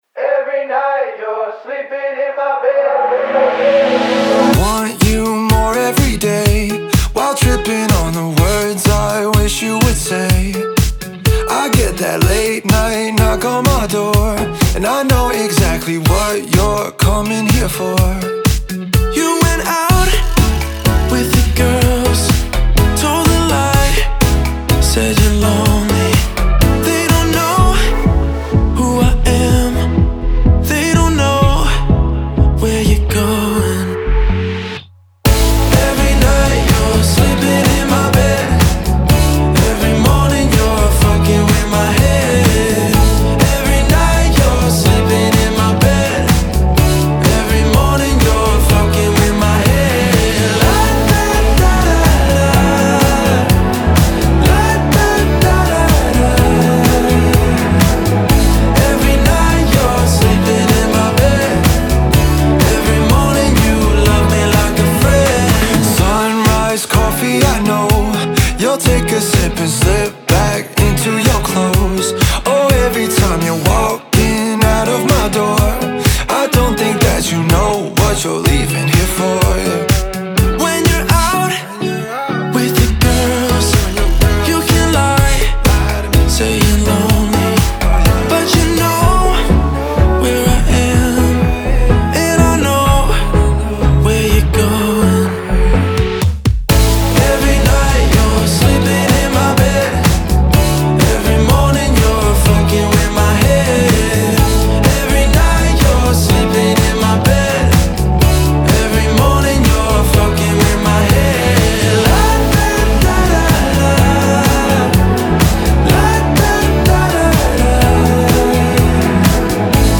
BPM125-125
Audio QualityPerfect (High Quality)
Indie-Pop song for StepMania, ITGmania, Project Outfox
Full Length Song (not arcade length cut)